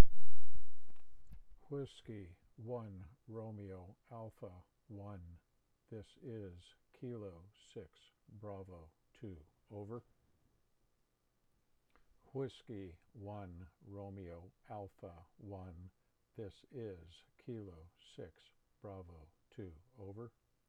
Call-Signs.wav